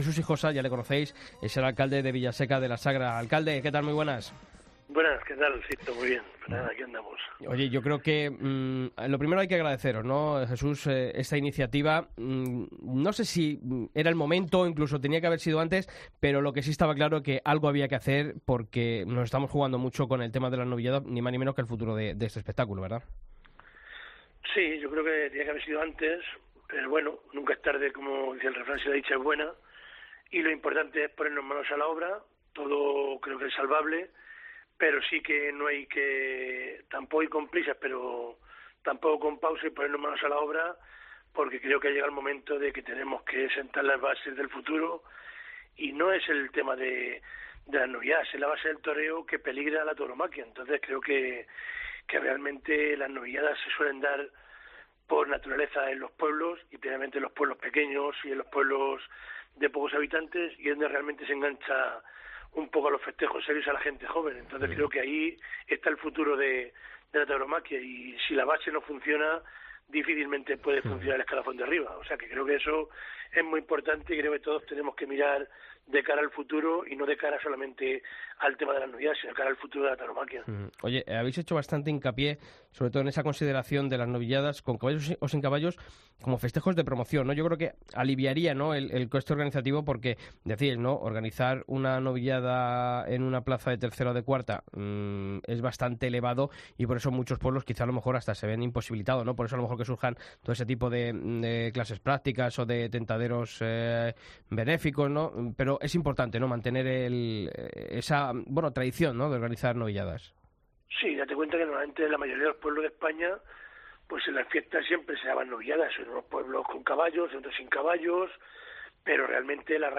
Jesús Hijosa, alcalde de Villaseca de la Sagra, en El Albero